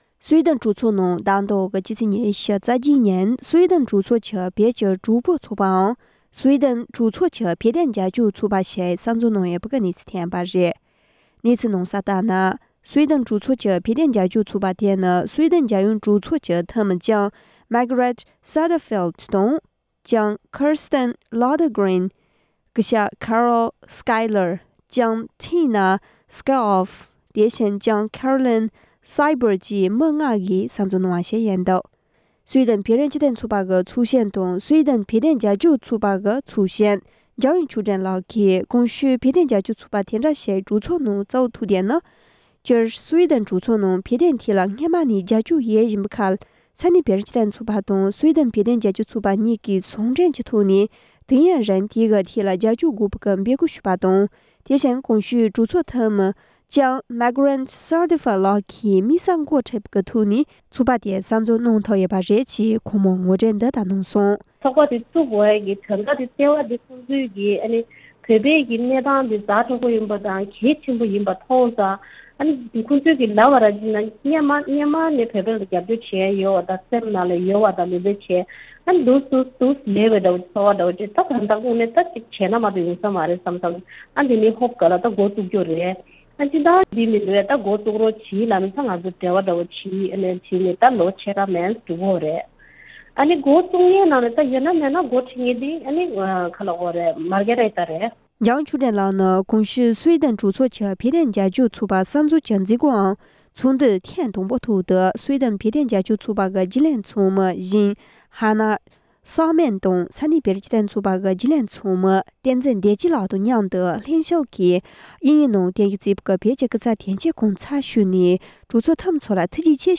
སྒྲ་ལྡན་གསར་འགྱུར། སྒྲ་ཕབ་ལེན།
བཅར་འདྲི་གནང་བ།